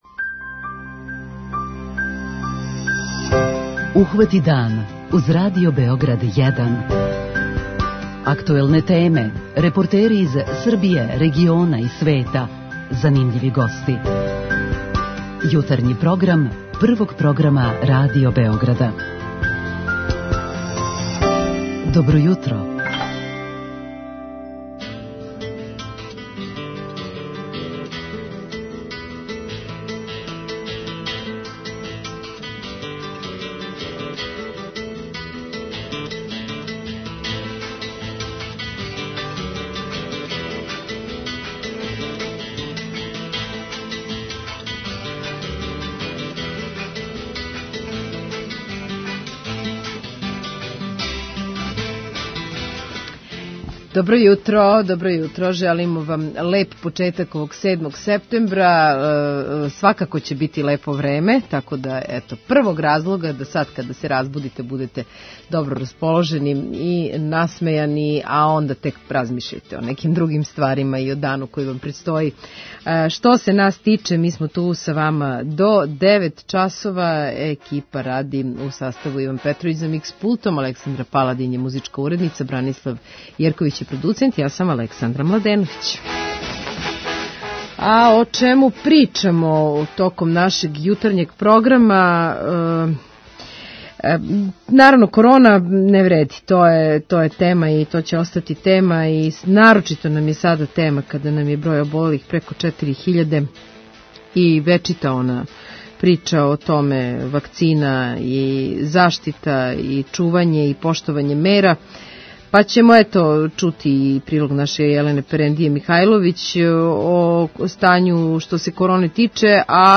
преузми : 26.98 MB Ухвати дан Autor: Група аутора Јутарњи програм Радио Београда 1!